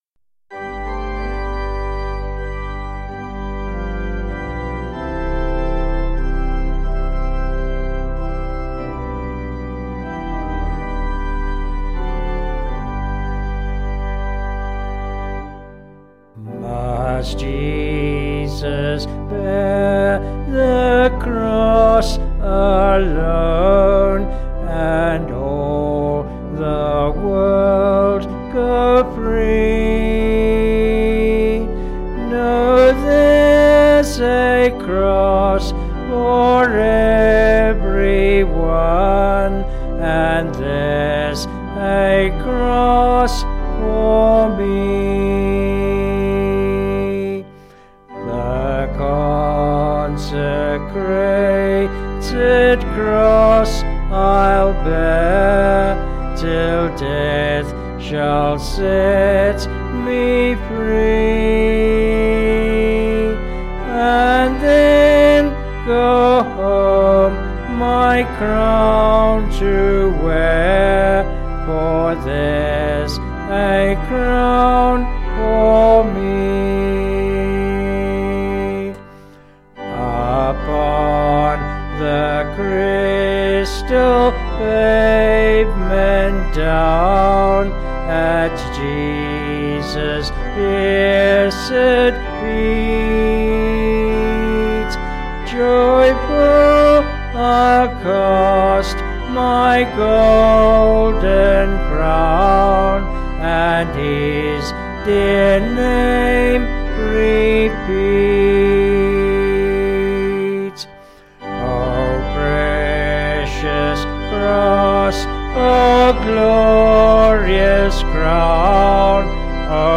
Vocals and Organ   264kb Sung Lyrics